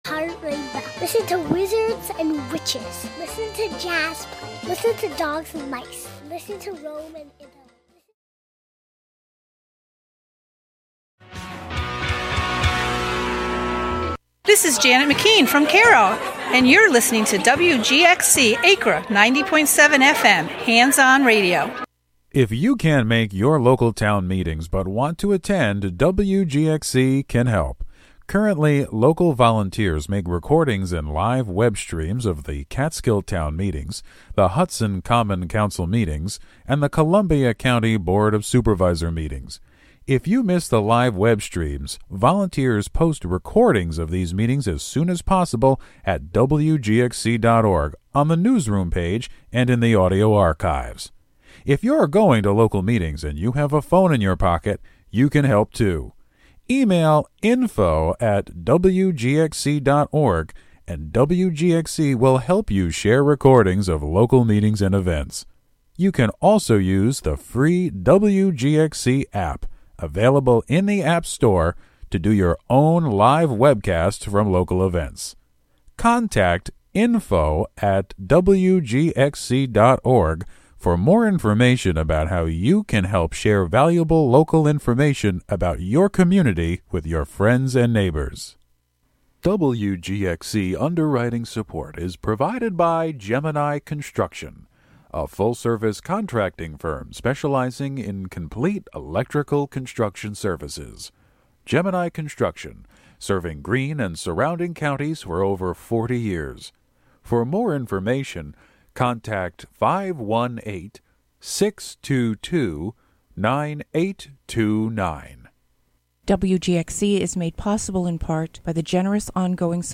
If you liked Car Talk , two wise guys answering questions about common automotive problems, you will love Tək Təlk , a weekly show by two wise guys answering questions about common tech glitches with computers, mobile devices, apps, and the Web.